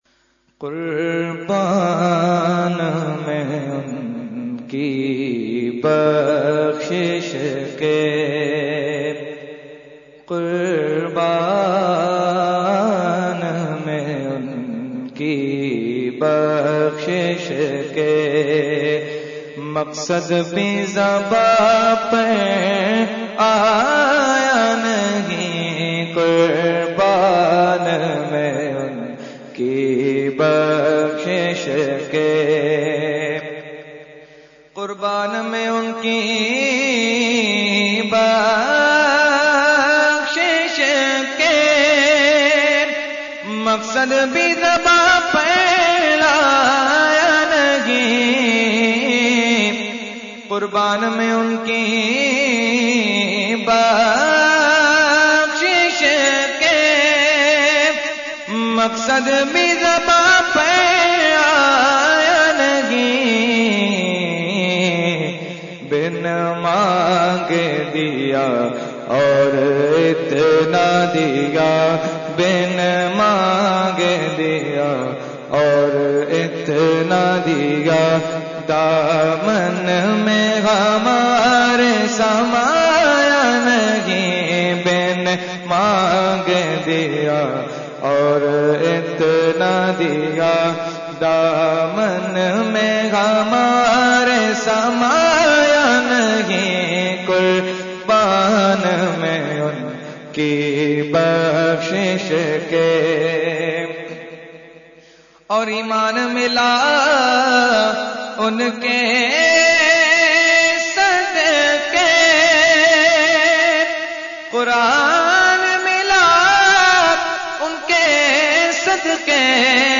Category : Naat | Language : UrduEvent : 11veen Shareef 2018-2